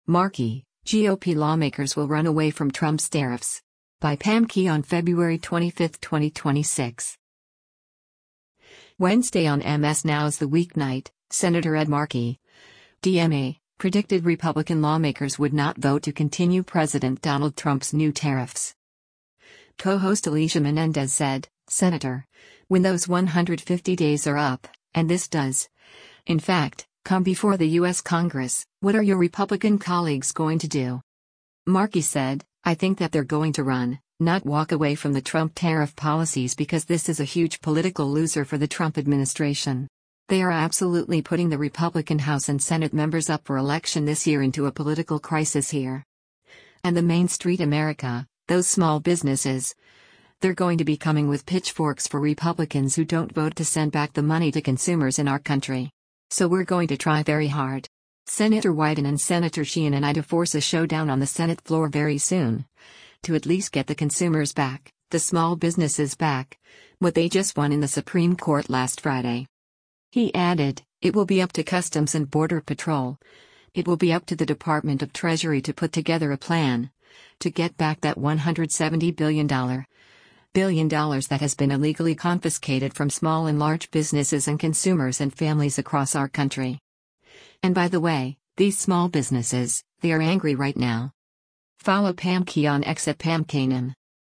Wednesday on MS NOW’s “The Weeknight,” Sen. Ed Markey (D-MA) predicted Republican lawmakers would not vote to continue President Donald Trump’s new tariffs.